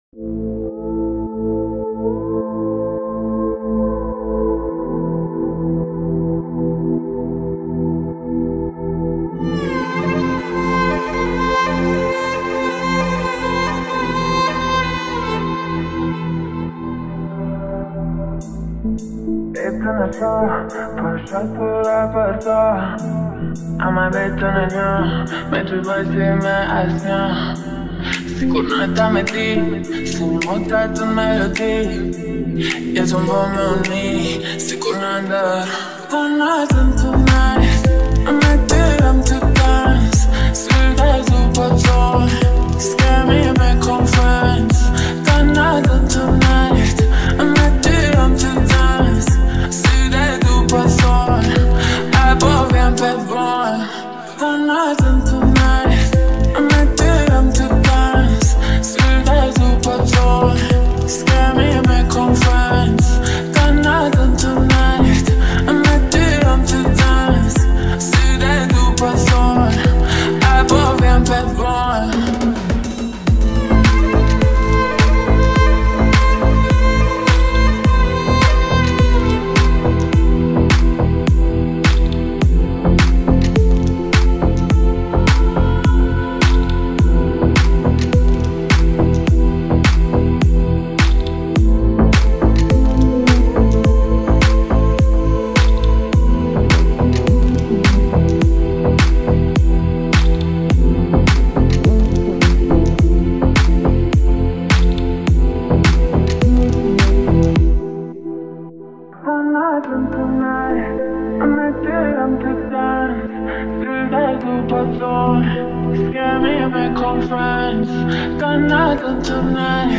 oriental deep house